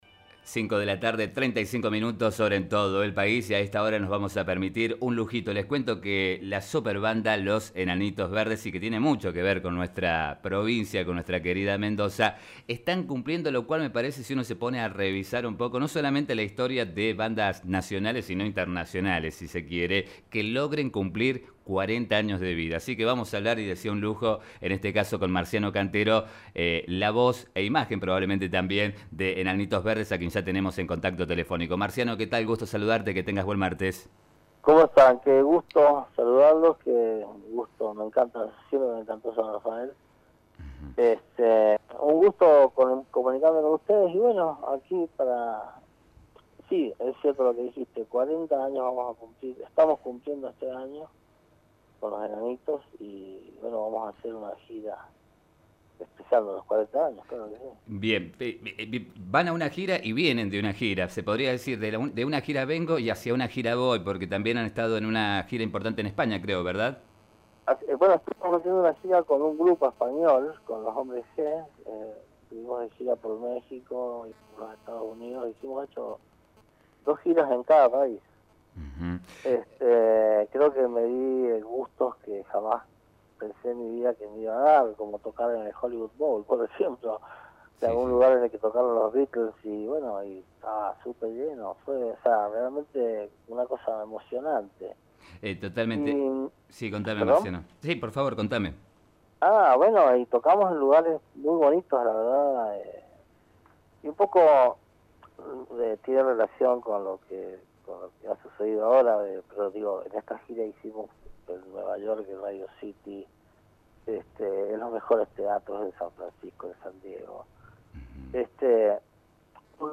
En diálogo con FM Vos (94.5), Cantero se refirió a este listado, a los 40 años de la banda y a la “energía” que le provocan los recitales.